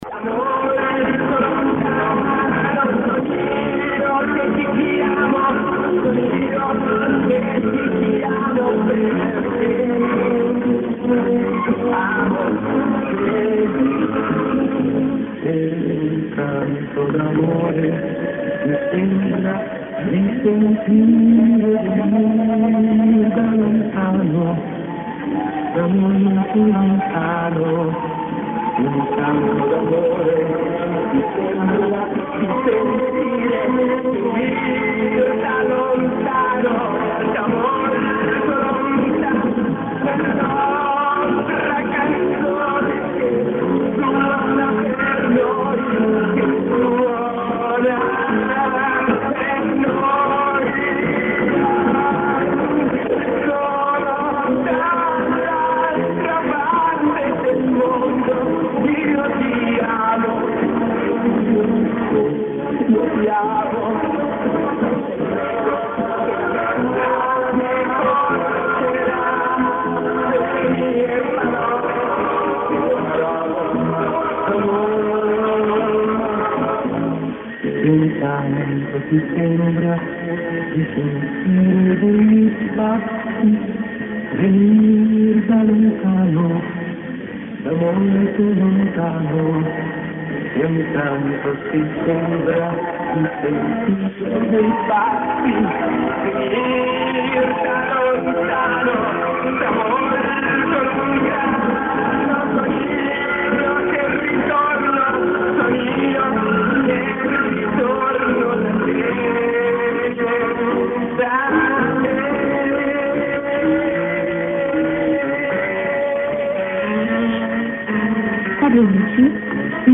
Запись с бобины.